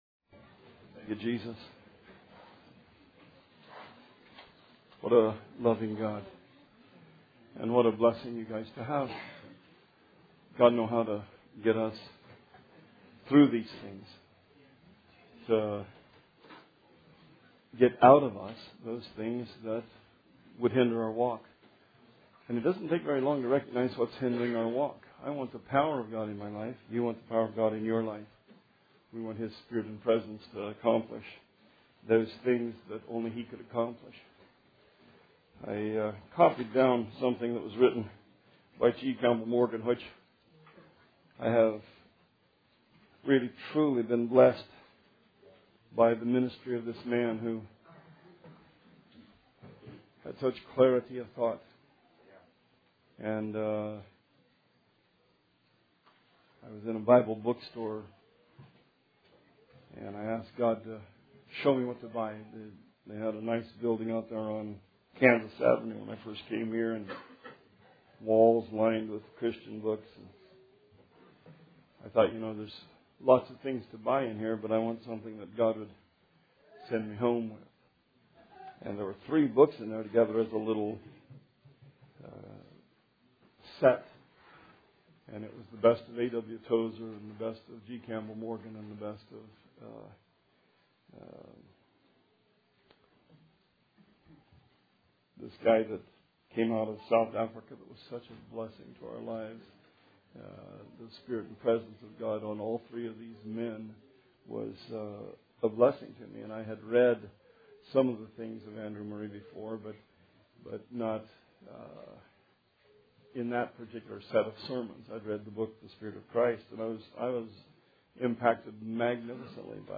Sermon 3/20/16